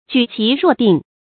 舉棋若定 注音： ㄐㄨˇ ㄑㄧˊ ㄖㄨㄛˋ ㄉㄧㄥˋ 讀音讀法： 意思解釋： 下棋子好像有預斷。